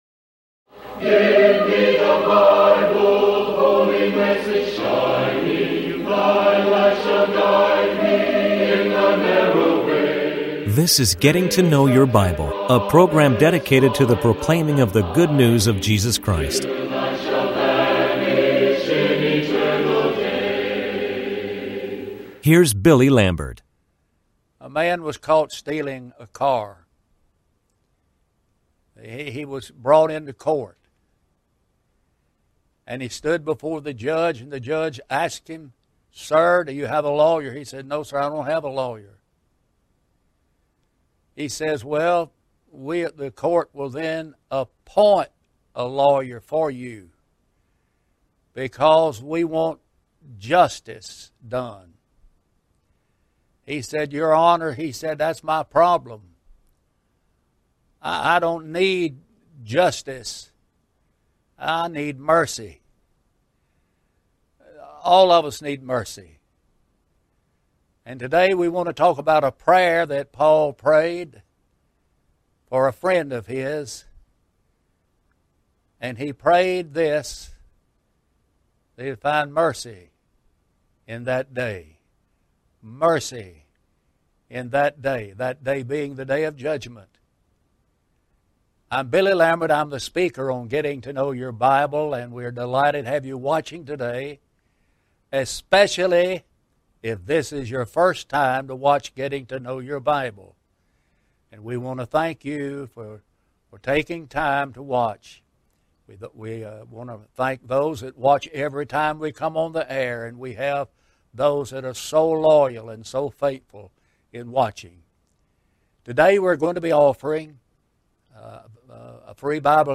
Getting To Know Your Bible is a TV program presented by churches of Christ, who are dedicated to preaching a message of hope and encouragement.